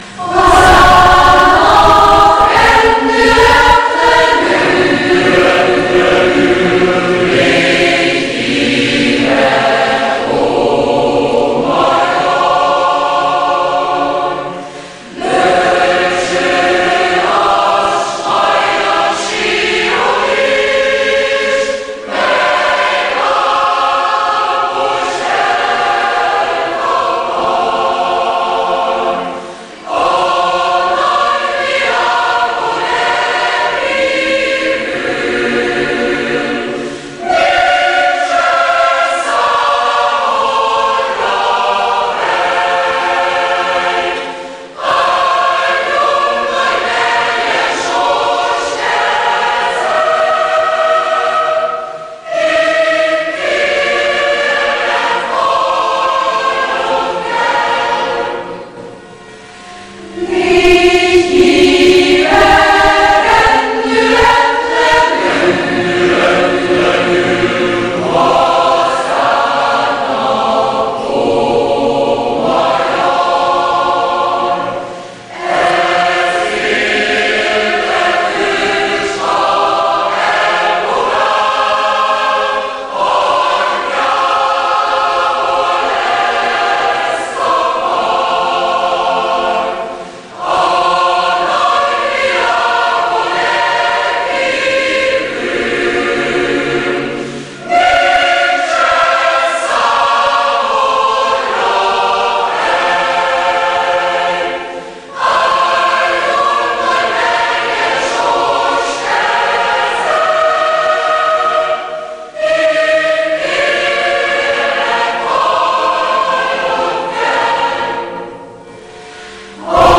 Aufnahme in Chortreffen in Békés, 04.06.2010
Előadja a kórustalálkozó egyesített kara.